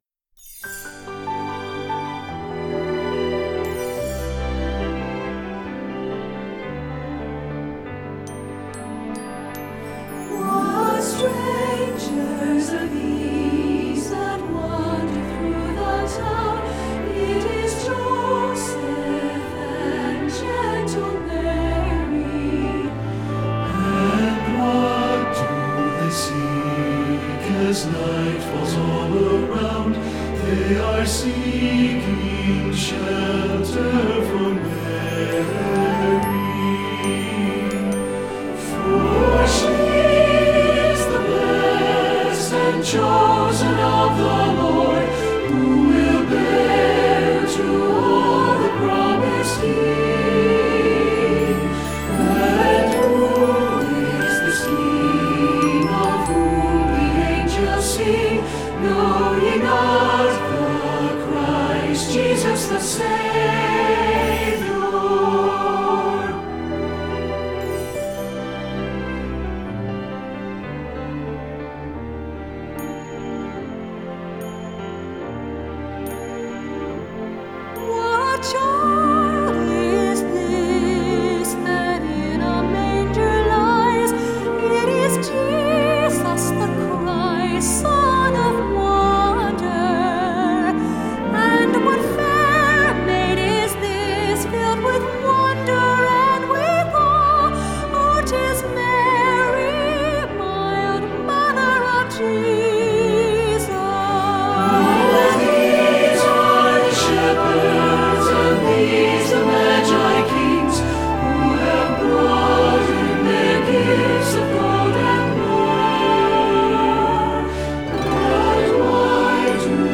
Composer: Traditional Scottish
Voicing: SATB